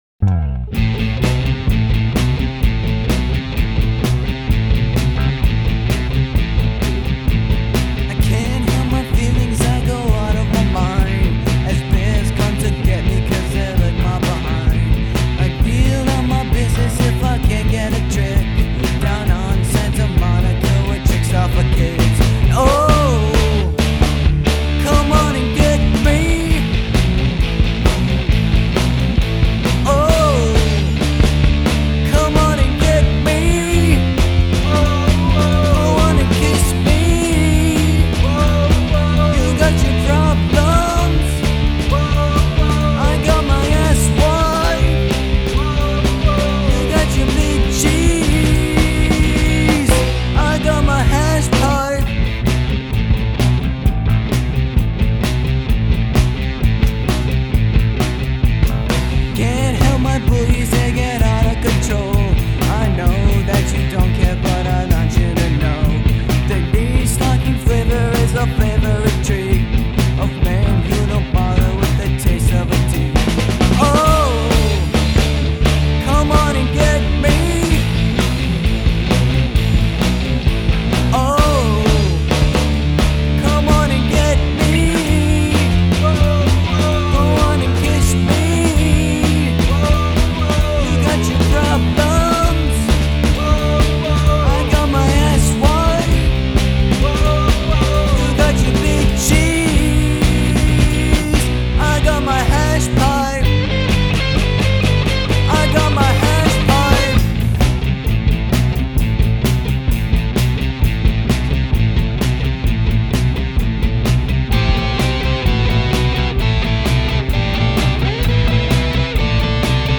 Viscount Studios